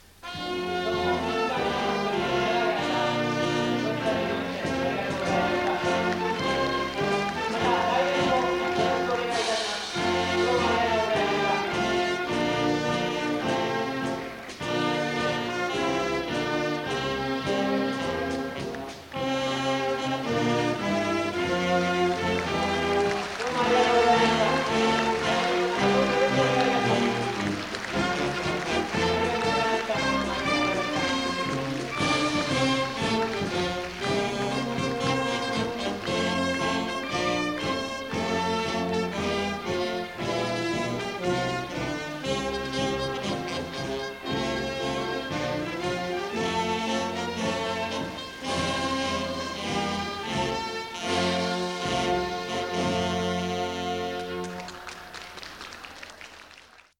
第9回 定期演奏会